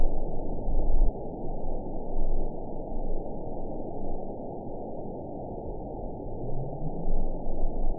event 910973 date 02/04/22 time 23:05:02 GMT (3 years, 3 months ago) score 7.93 location TSS-AB01 detected by nrw target species NRW annotations +NRW Spectrogram: Frequency (kHz) vs. Time (s) audio not available .wav